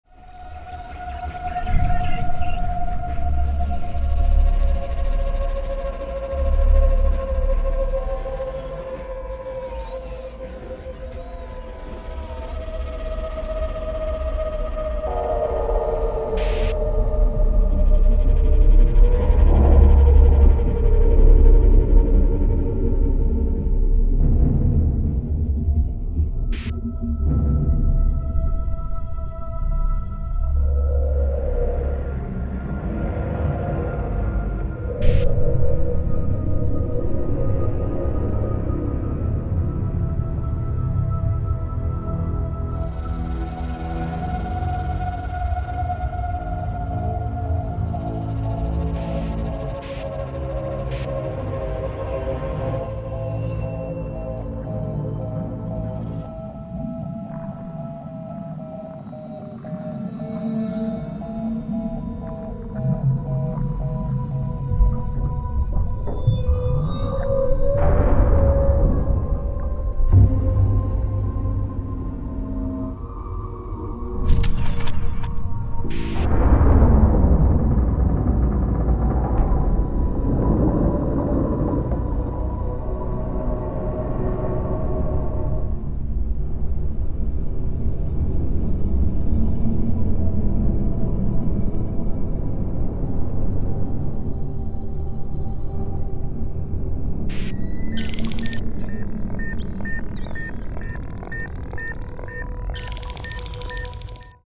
"The Prodigy", a hard techno style with breakbeat